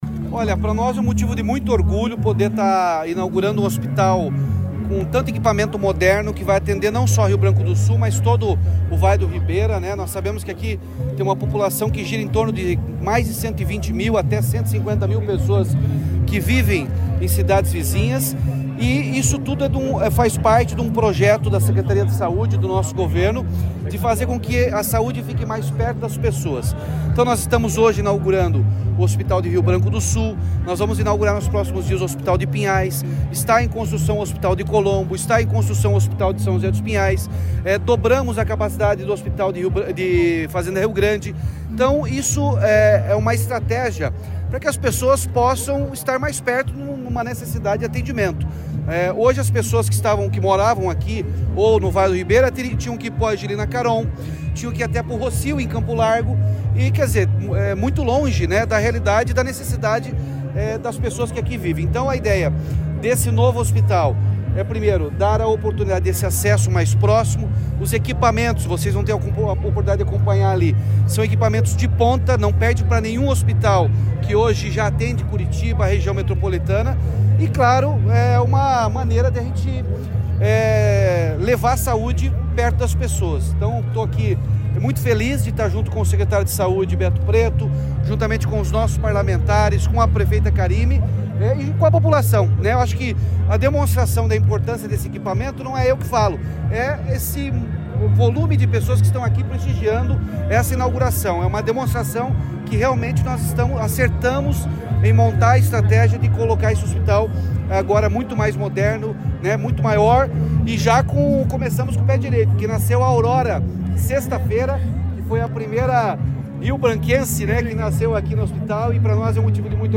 Sonora do governador Ratinho Junior sobre o novo Hospital e Maternidade Municipal de Rio Branco do Sul